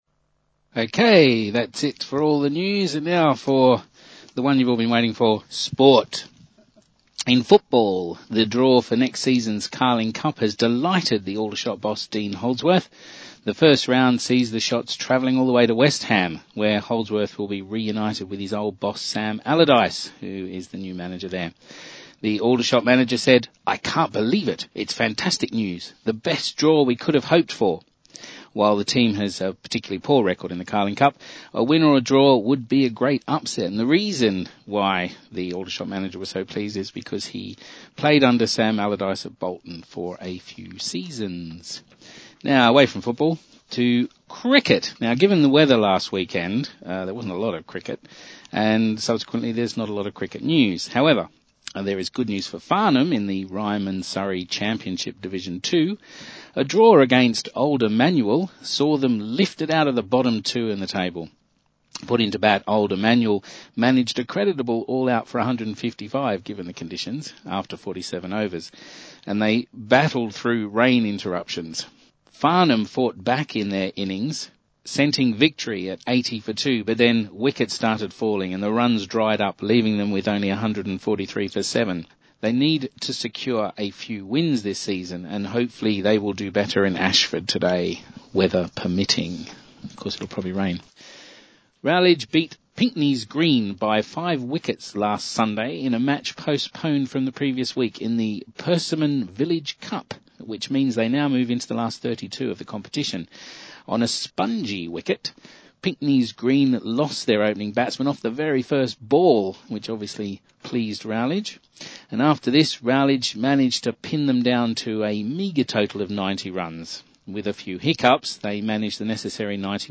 I’ve included two of the tracks from this week’s Talking Newspaper.